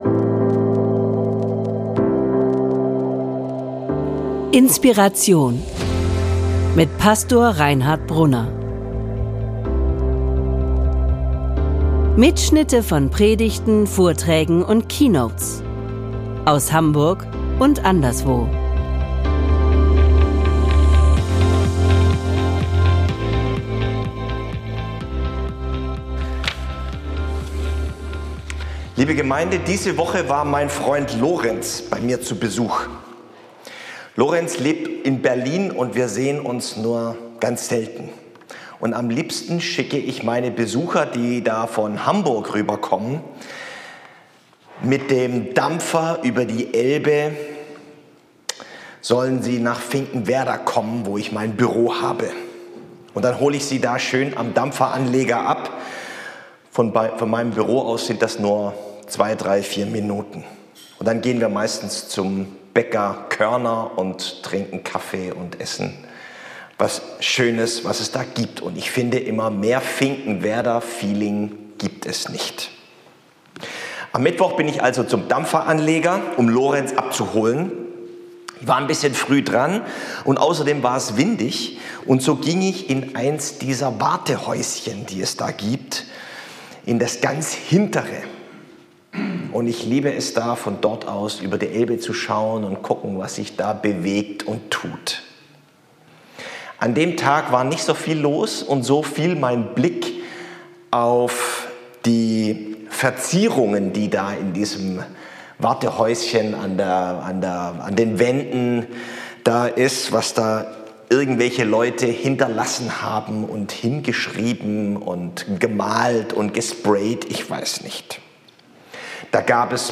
Jedenfalls hat mich das Graffito zur Predigt am heutigen Sonntag Trinitatis inspiriert und auf eine ungwöhnliche Spur gebracht...